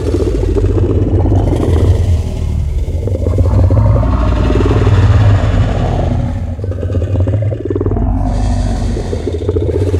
Sfx_creature_hiddencroc_chase_loop_01.ogg